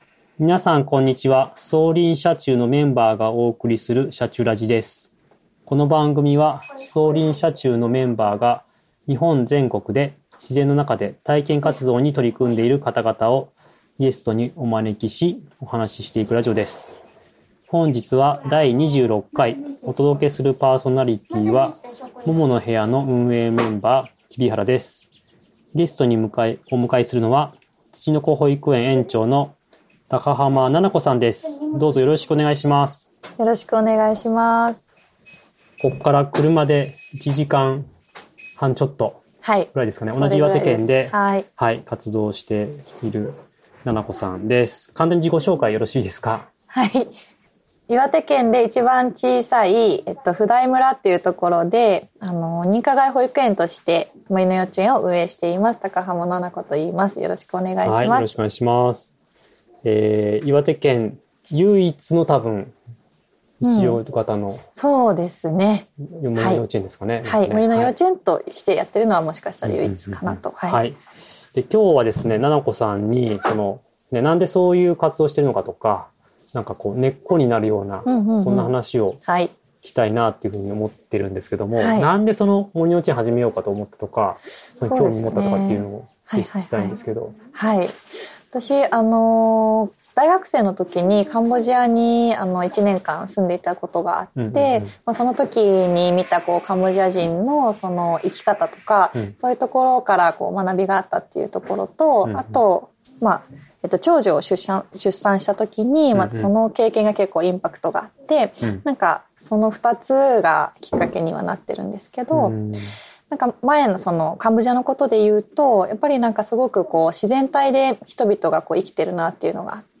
「シャチュラジ」この番組は、走林社中のメンバーが日本全国で自然の中での体験活動に取り組んでいる方々をゲストにお招きし、お話ししていくラジオです。